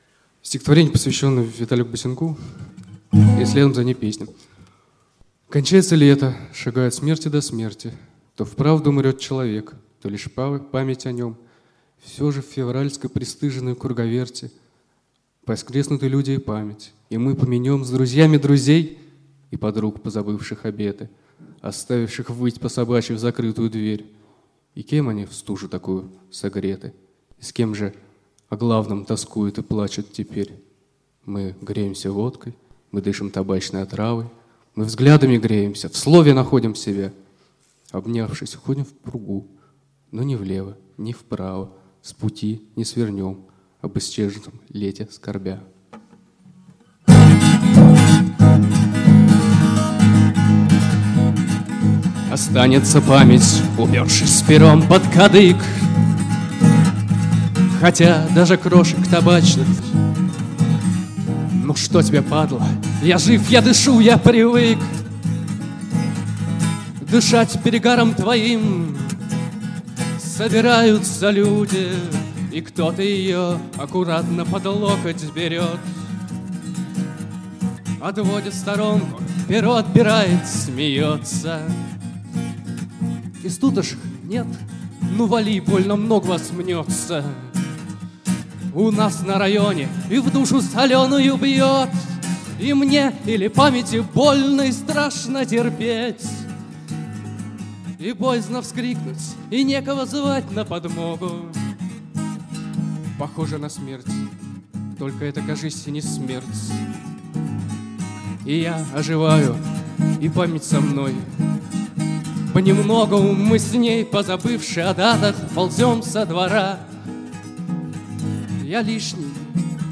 Конкурсная программа.